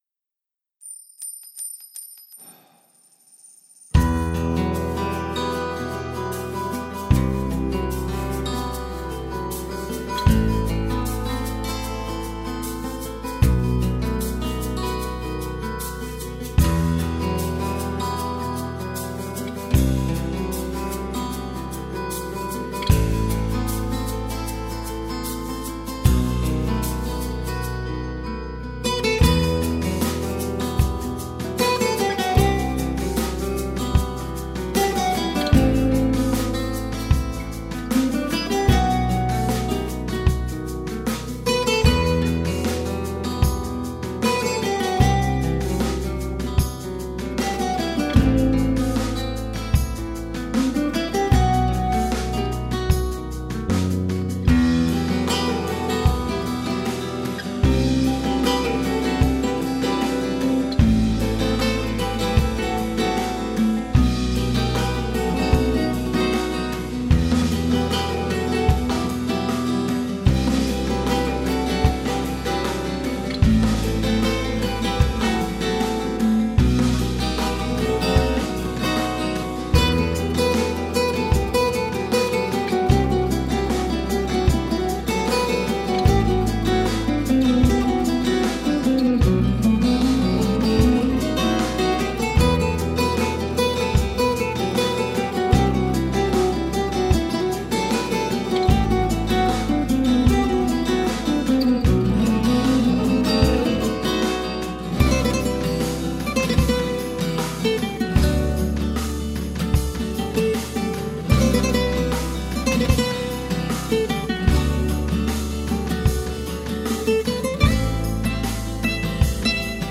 Гитарная музыка